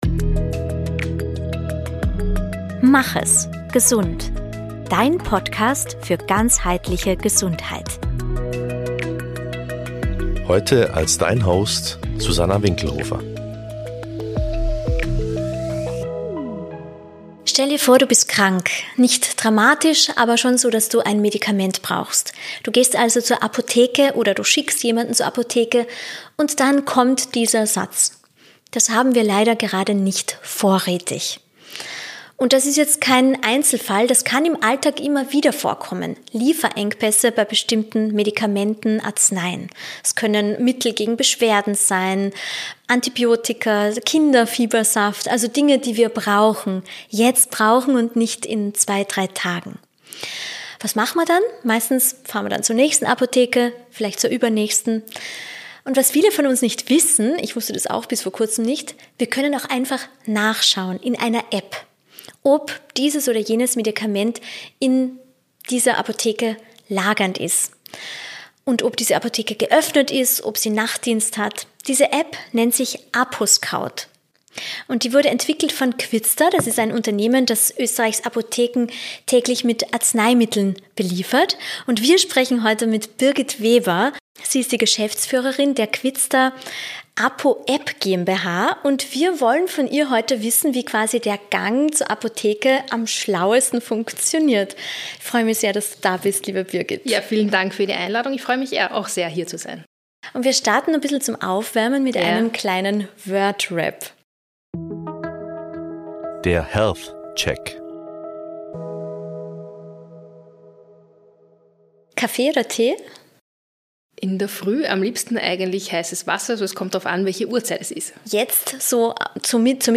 Im Gespräch erzählt sie, wie aus einem gesellschaftlichen Problem eine Innovation wurde – und warum das Ziel ausdrücklich nicht ist, die Apotheke vor Ort zu ersetzen.